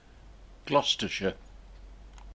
Gloucestershire (/ˈɡlɒstərʃər/
GLOST-ər-shər, /-ʃɪər/ -sheer; abbreviated Glos.)[3] is a ceremonial county in South West England.
En-gloucestershire.ogg.mp3